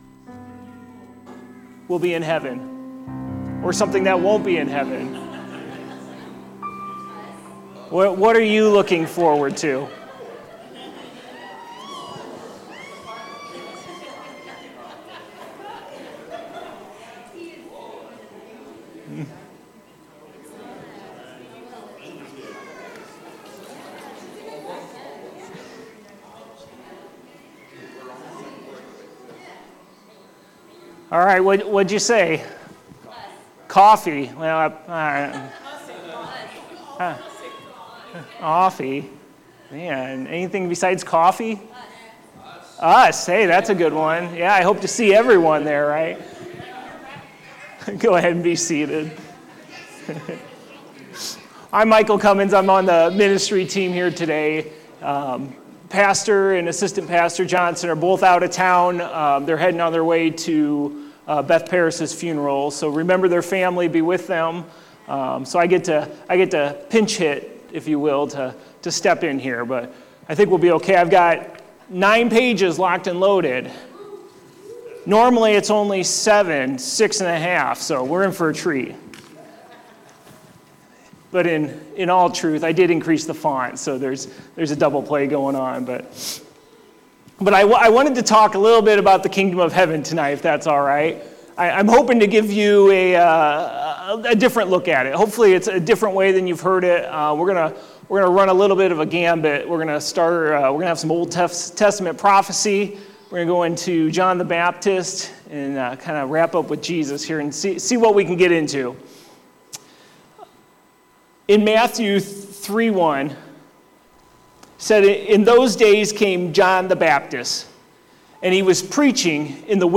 Wednesday Service Thy Kingdom Come